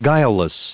added pronounciation and merriam webster audio
377_guileless.ogg